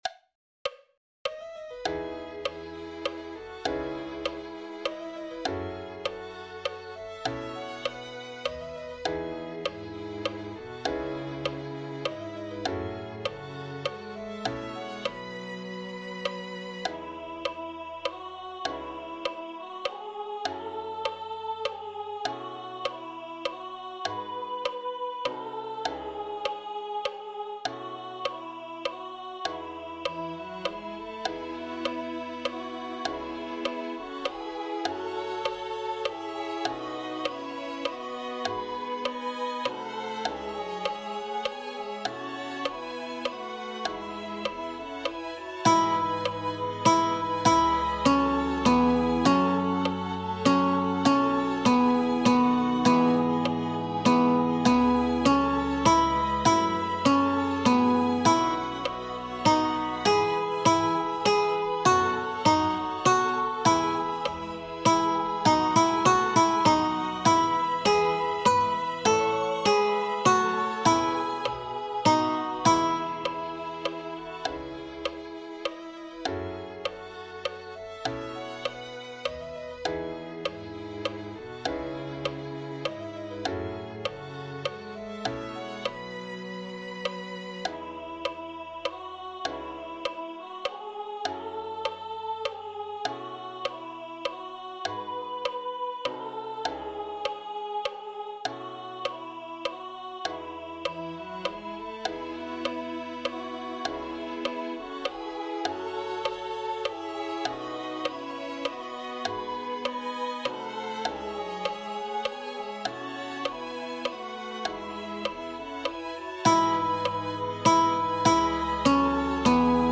This page contains recordings of the notes for the alto voice parts for the song that will be presented during the Christmas Sunday service (December 21, 2025).
Note that blank measures for the parts are not skipped; it follows the music as written so if you hear silence that's because there's nothing written for your part in that portion of the recording (i.e., your device isn't broken and your ears still work).
Come_Thou_Long_Expected_Jesus.E.AltoLoud.mp3